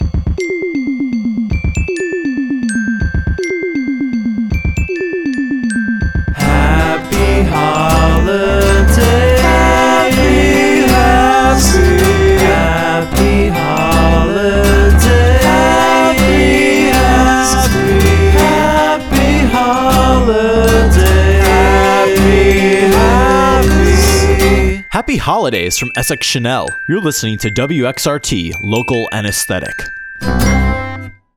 Vocals, Banjo & Guitar
Drums
Clarinet & Saxophone
Trumpet
Tuba